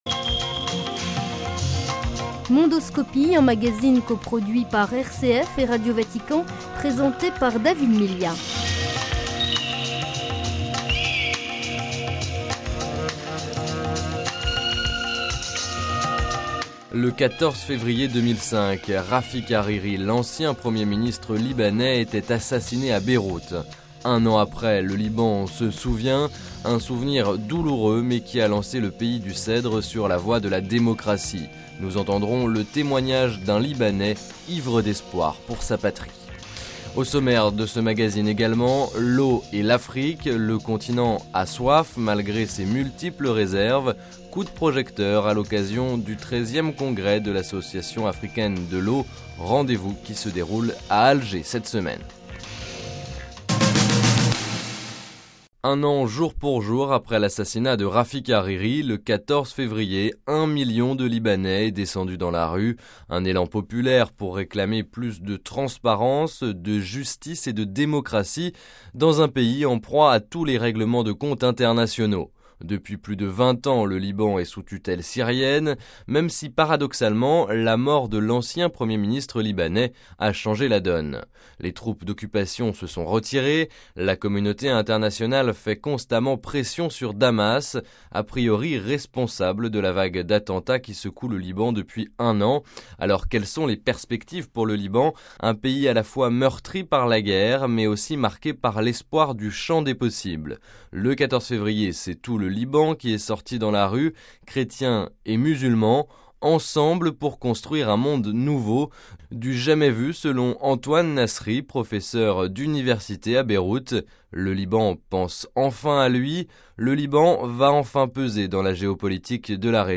Nous entendrons le témoignage d’un libanais, ivre d’espoir pour sa patrie.